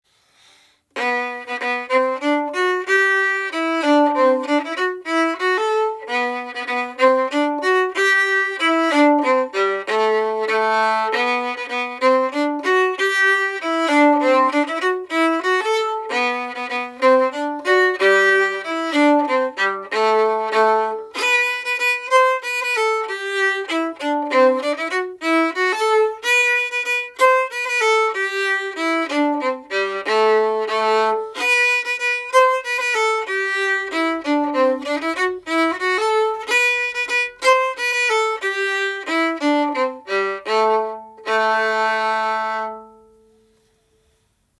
Key of G fiddle chords.
Mairi’s Wedding is the melody to a Scottish folk song.